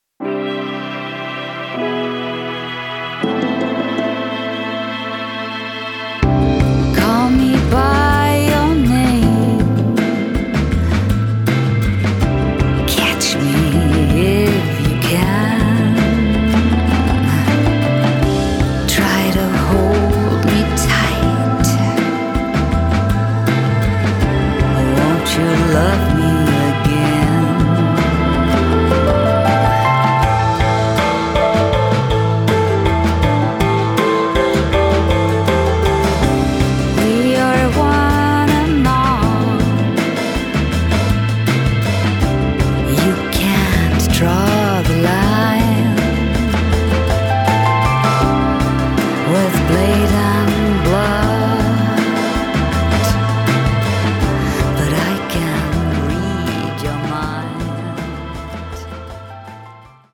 auf eine atmosphärische, psychedelische Grundstimmung